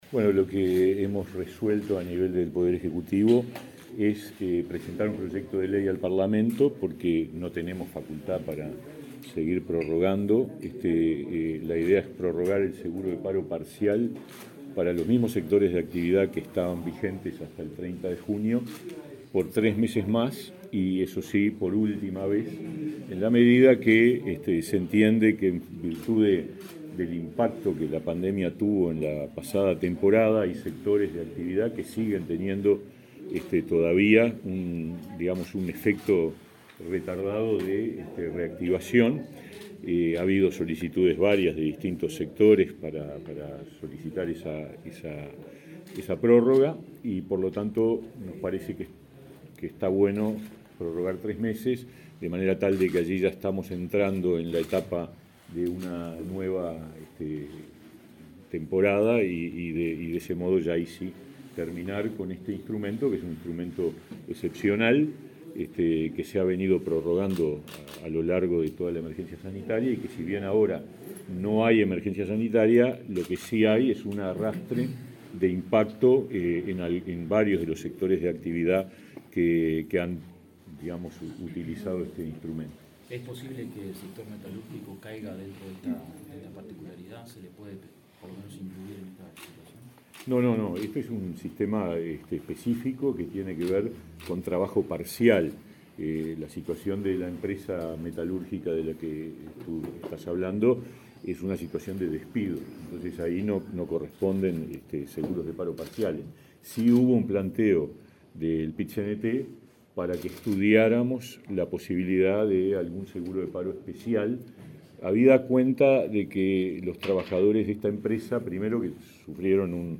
Declaraciones del ministro de Trabajo, Pablo Mieres